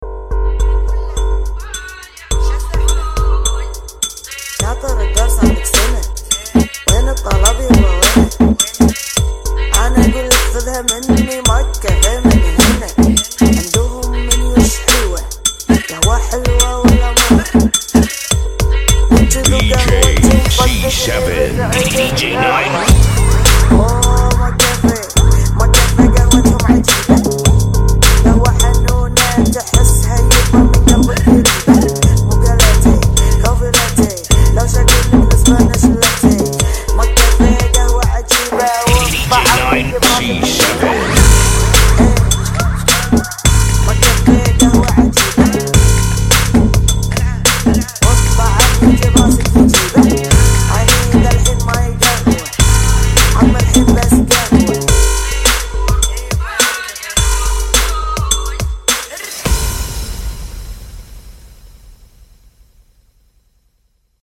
TRAP
105 BPM